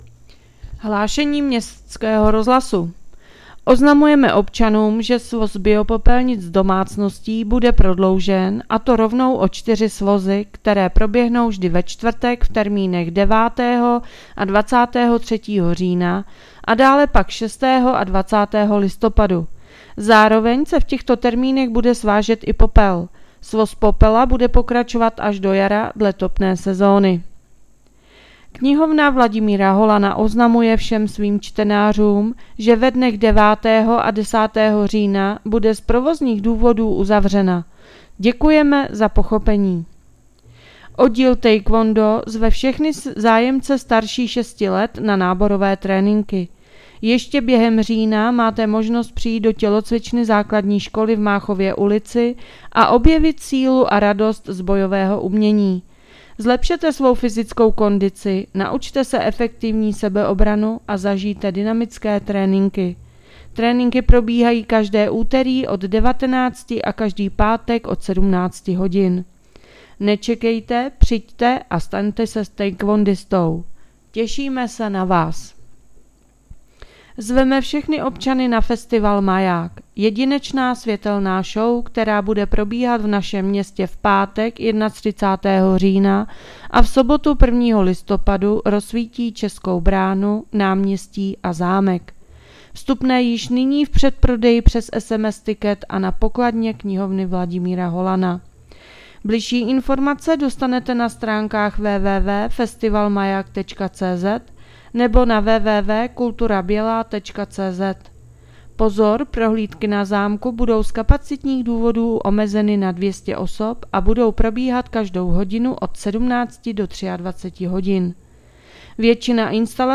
Hlášení městského rozhlasu 6.10.2025